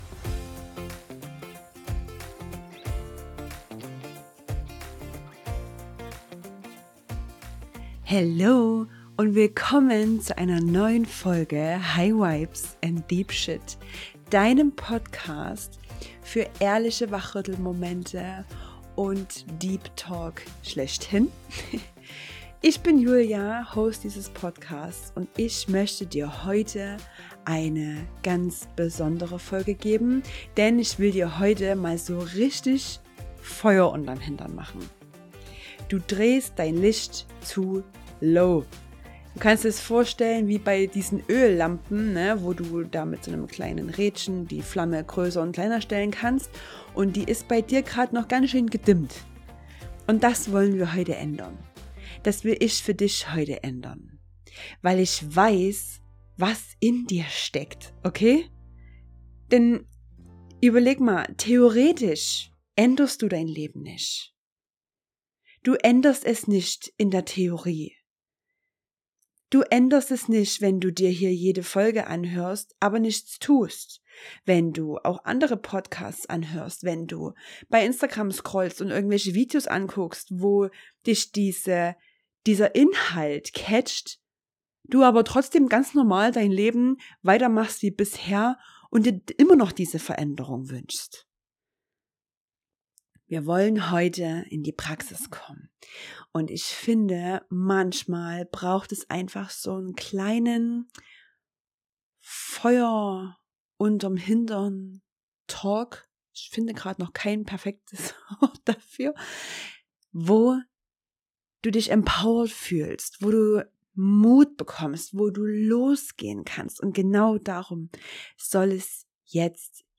Ein Power Talk, der dir Feuer unter dem Hintern macht – ehrlich,